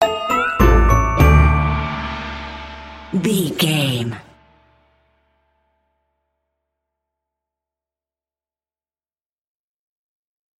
Aeolian/Minor
D
strings
horns
percussion
silly
circus
goofy
comical
cheerful
perky
Light hearted
quirky